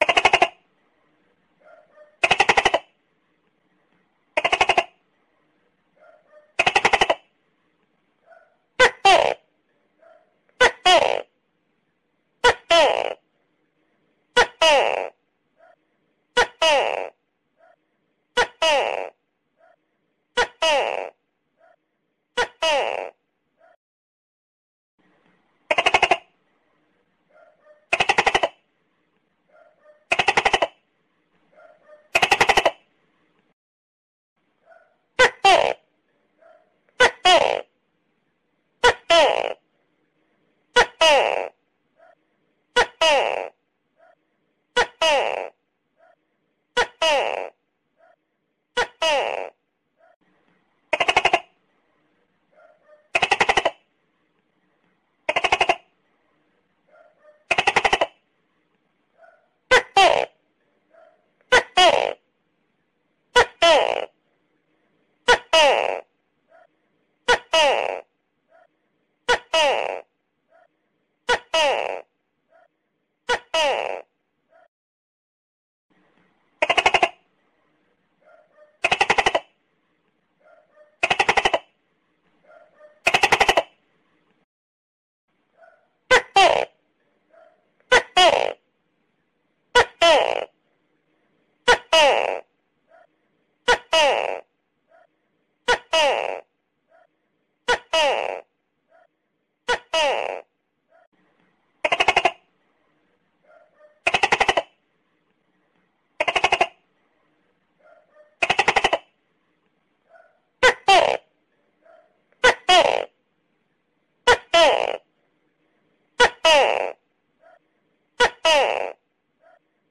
Tiếng động vật 480 lượt xem 10/03/2026
Download tiếng kêu của tắc kè mp3 chất lượng cao, tải file mp3 tiếng tắc kè kêu trong nhà mp3 hay nhất, chuẩn nhất.
Tiếng Tắc Kè kêu